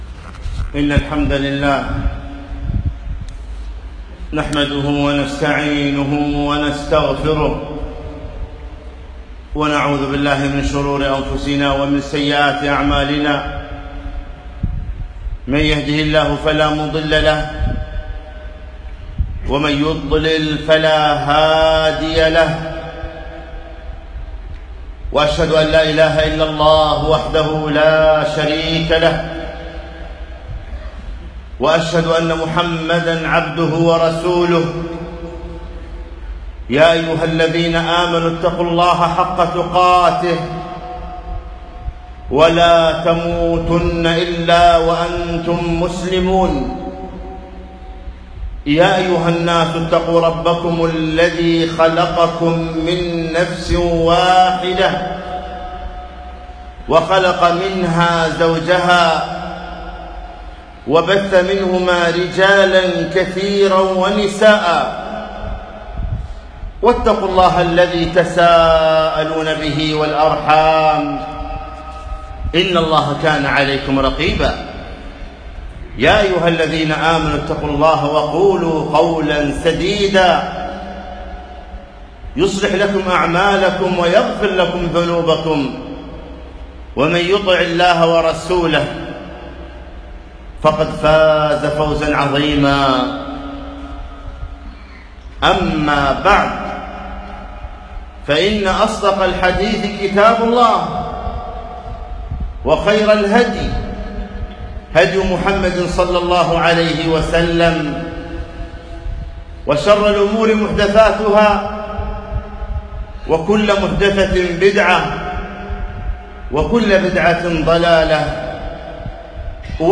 خطبة - إن ربي لطيف لما يشاء